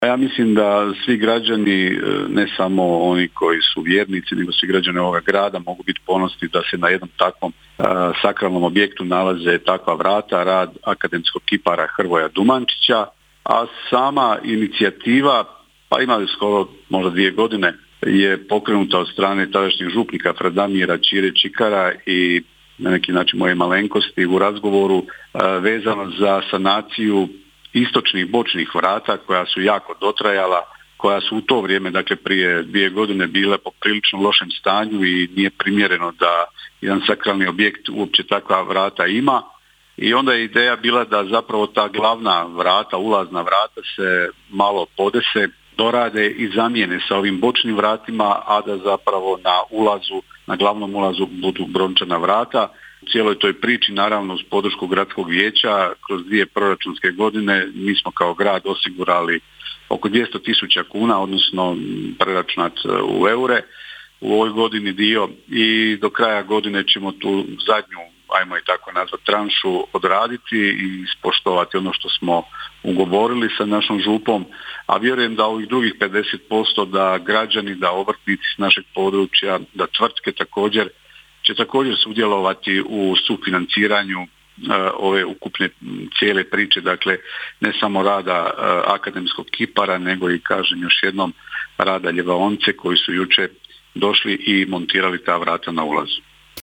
Gradonačelnik Drniša Josip Begonja nam je kazao:
Josip-Begonja-web-izjava-vrata.mp3